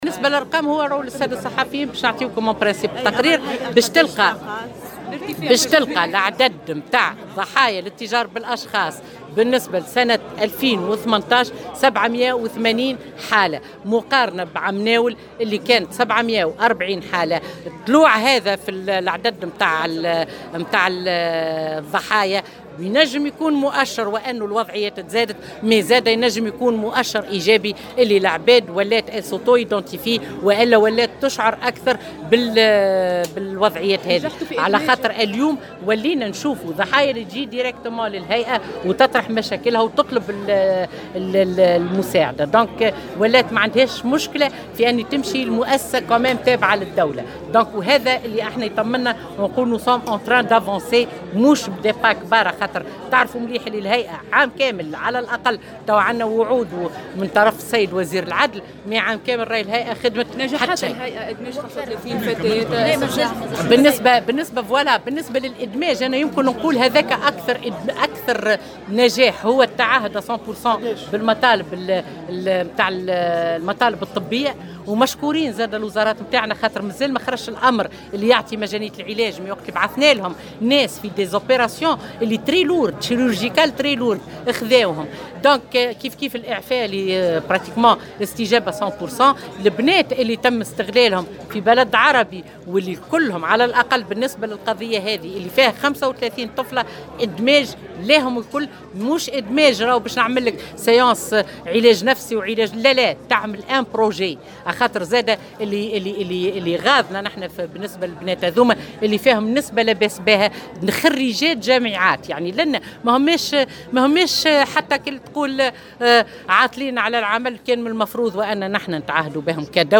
أكدت رئيسة الهيئة الوطنية لمكافحة الاتجار بالأشخاص روضة العبيدي في تصريح لمراسل الجوهرة "اف ام" اليوم الأربعاء 23 جانفي 2019 أن الهيئة سجلت 780 حالة اتجار بالأشخاص في تونس سنة 2018 بعد أن كانت في حدود 740 حالة في السنة الماضية.